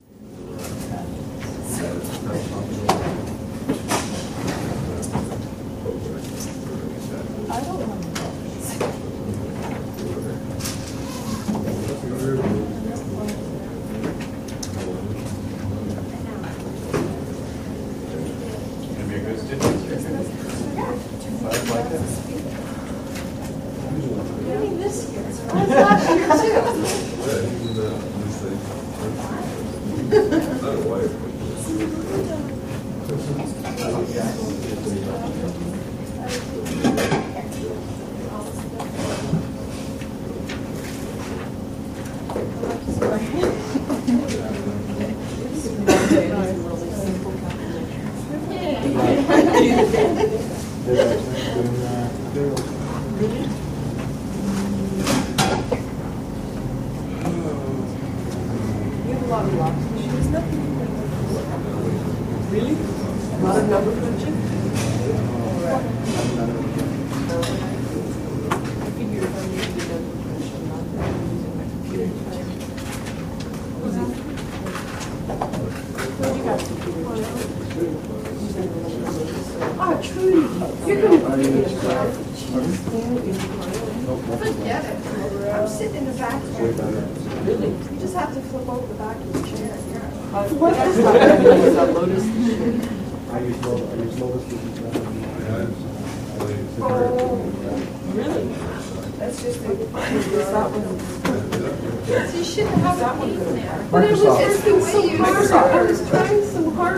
Student Walla
Classroom Light Walla With AC